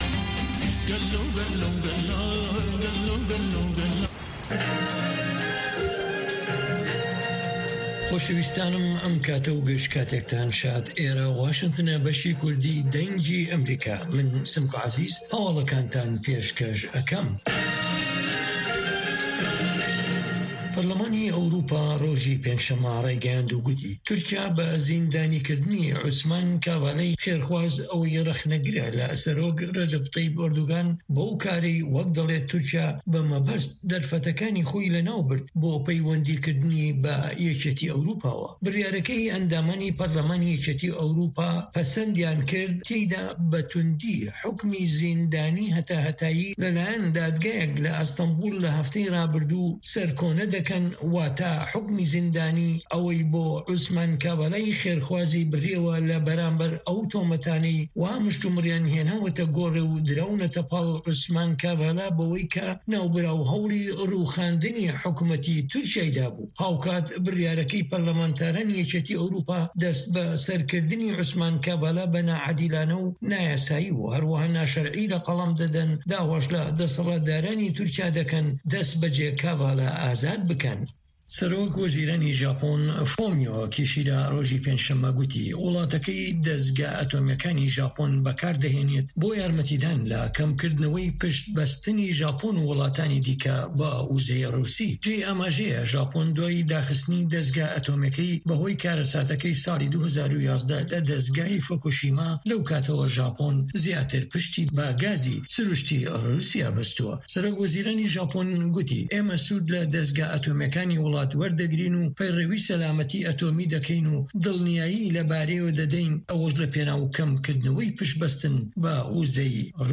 Nûçeyên Cîhanê 2